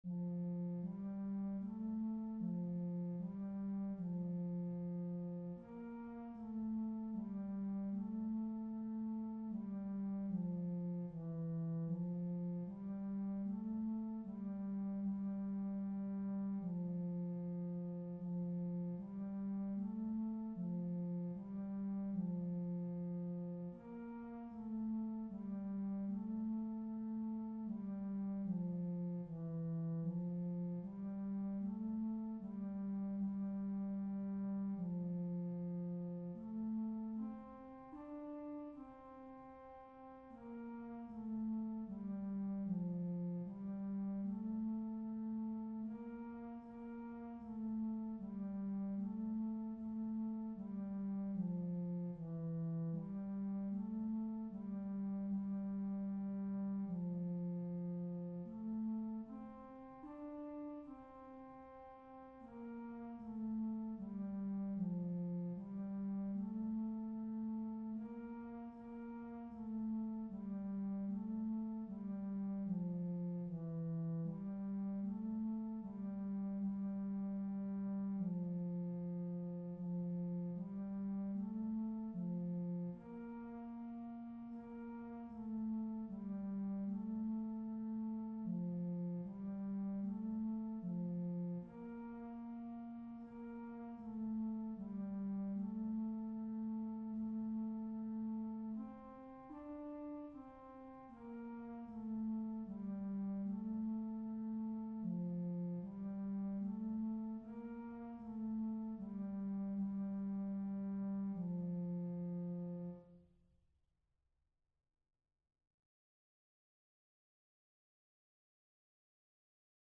All seven of the background music selections are historic masterworks of the common practice period (1450-1950) based upon the text of the Gregorian Plainchant hymn “Ave Verum Corpus”, Hail True Body.
To audition the core thematic hymn, click the ▶ button to listen to the Gregorian Plainchant monody Ave Verum Corpus, attributed to Pope Innocent VI (1282-1362), or play the music in a New Window
Ave-Verum-Corpus--Gregorian-Plainchant-Monody.mp3